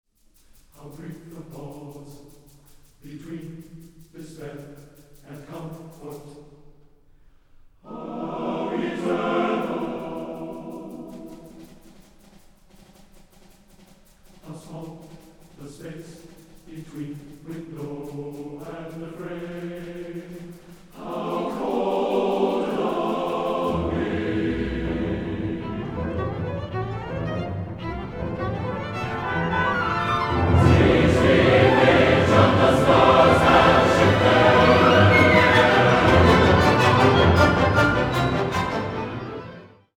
Instrumentation: solo violin, SATB chorus, orchestra